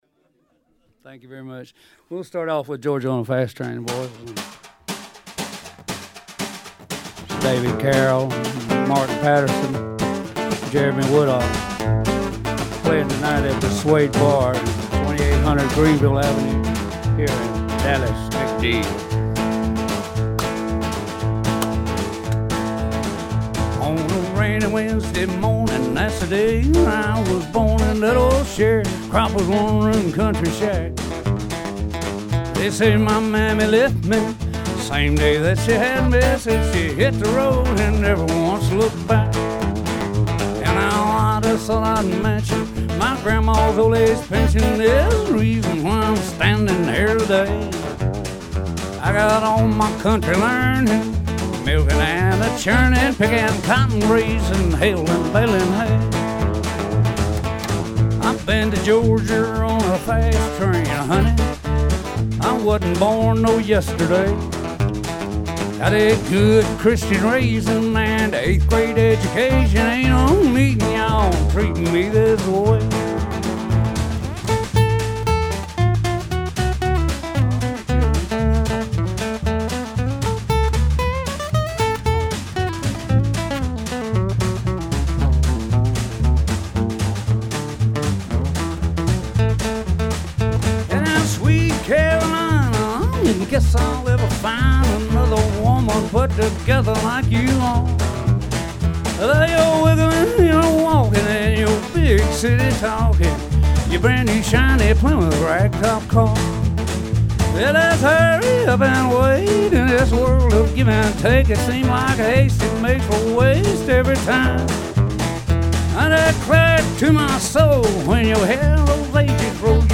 told me stories on my afternoon Dallas/ Ft.Worth radio show